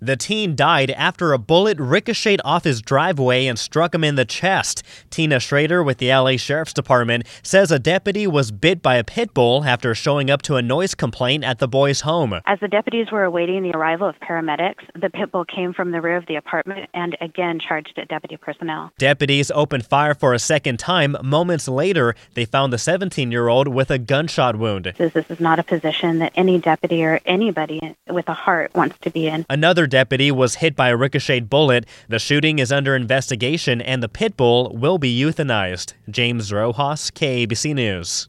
Field Reporter